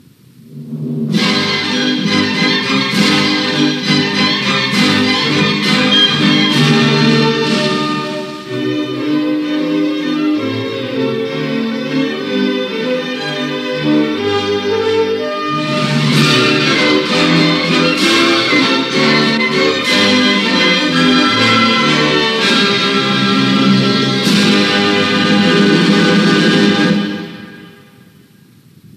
Sintonia de l'emissora
en versió orquestral.
Estava basada en un toc militar adaptat